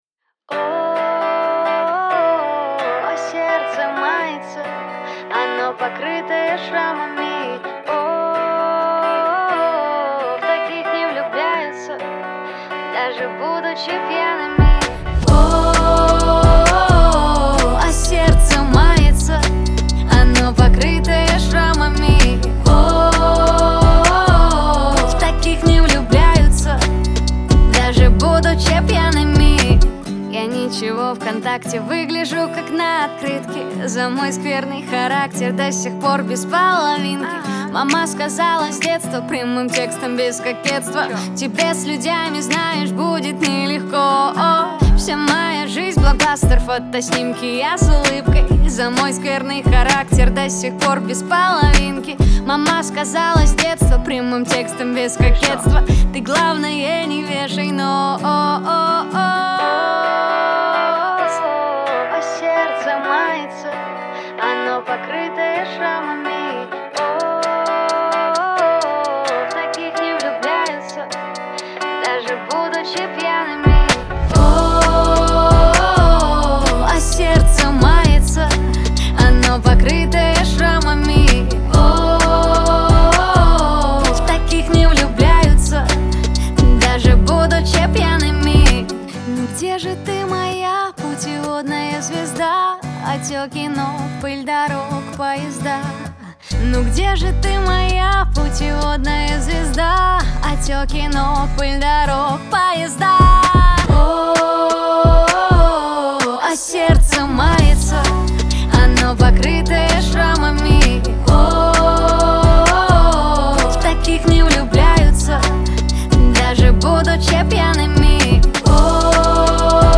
проникновенная поп-баллада